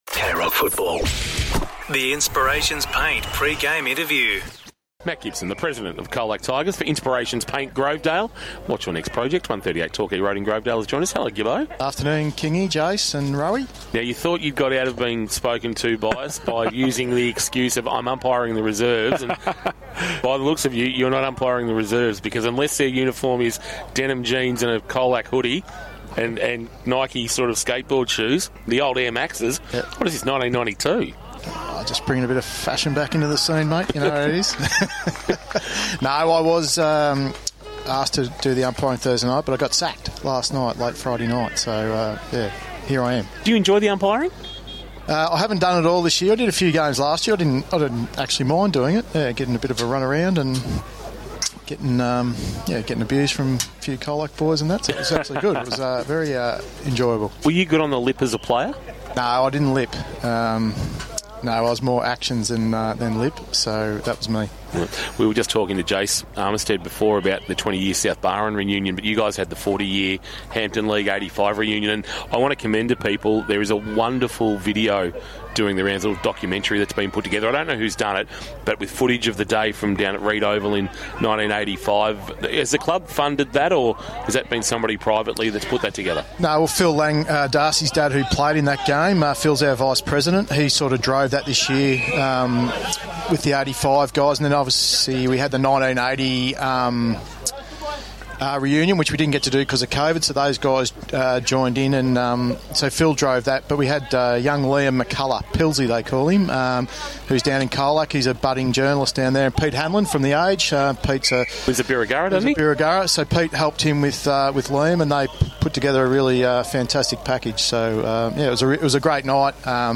Pre-match interview